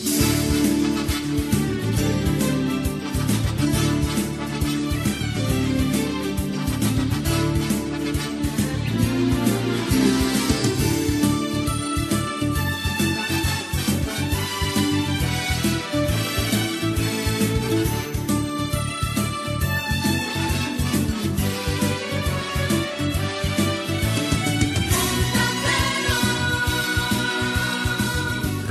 Sintonia instrumental de la ràdio amb final cantat.